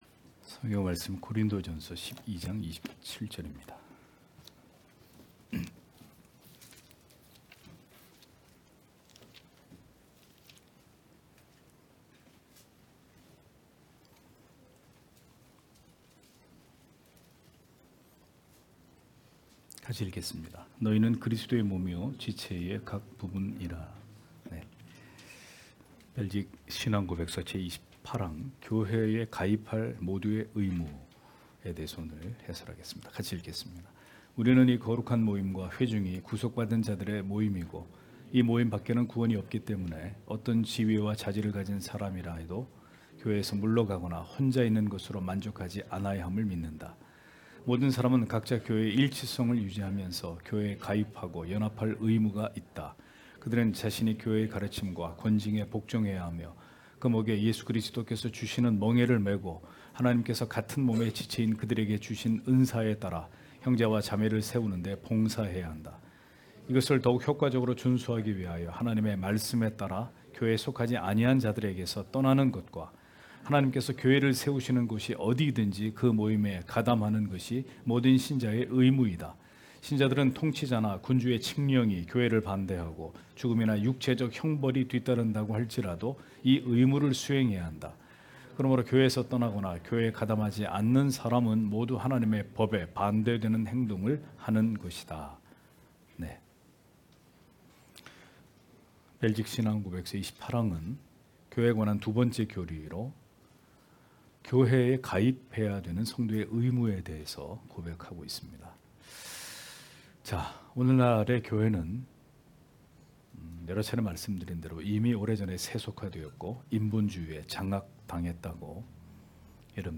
주일오후예배 - [벨직 신앙고백서 해설 32] 제28항 교회에 가입할 모두의 의무(고전 12장 27절)